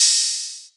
DDW2 OPEN HAT 7.wav